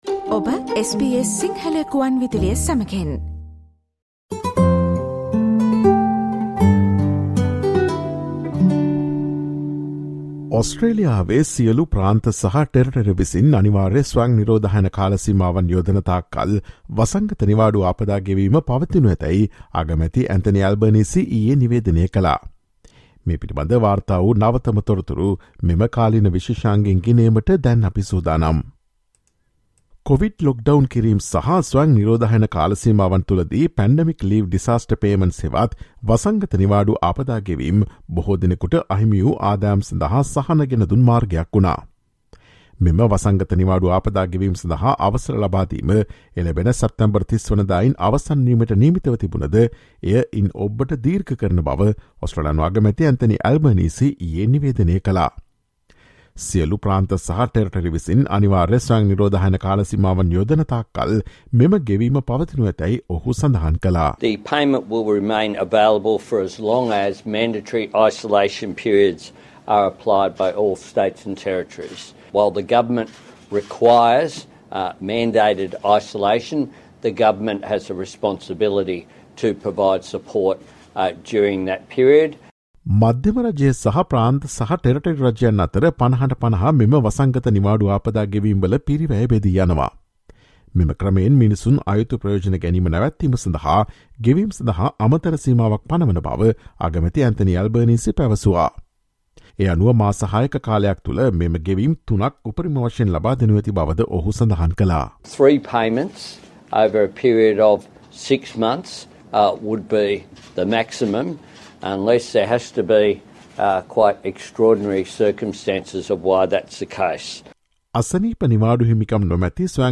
Listen to SBS Sinhala Radio's current affairs feature broadcast on Thursday, 15 September with the latest information on extension of pandemic leave payments.